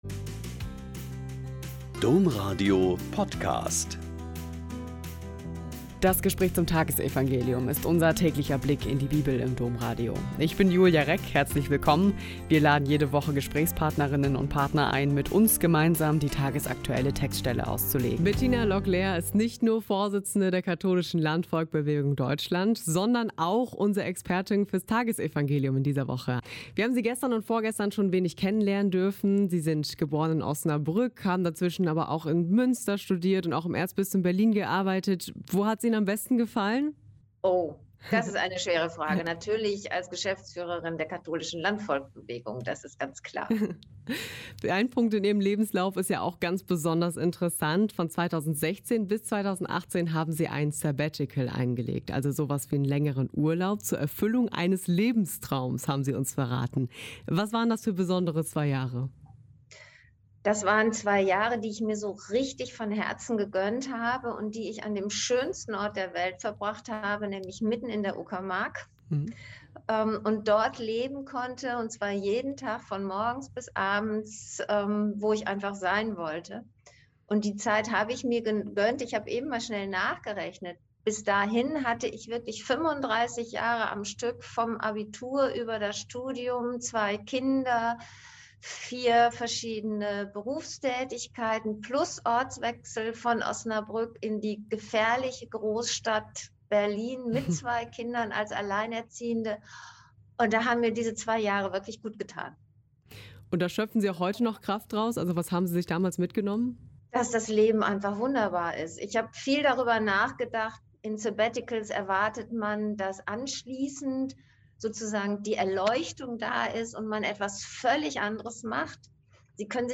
Mt 10,1-7 - Gespräch